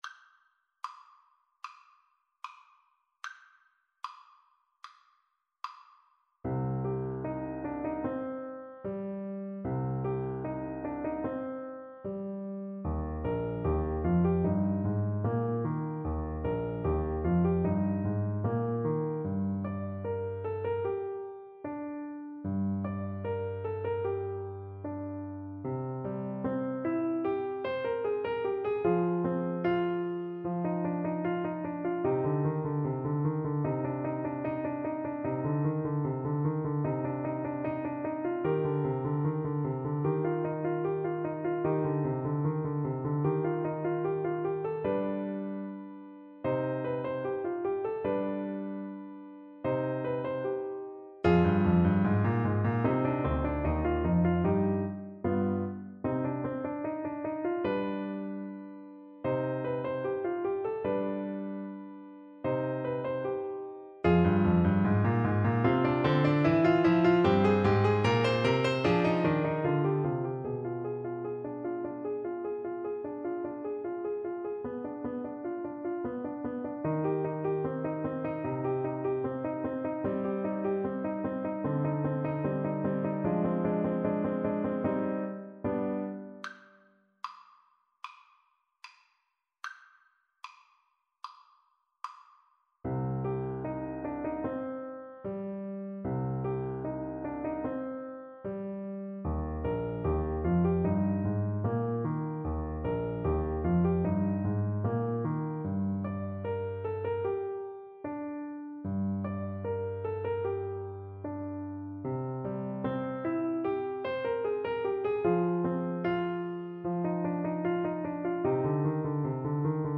Trumpet
C minor (Sounding Pitch) D minor (Trumpet in Bb) (View more C minor Music for Trumpet )
Allegro Moderato (View more music marked Allegro)
4/4 (View more 4/4 Music)
Bb4-G6
Classical (View more Classical Trumpet Music)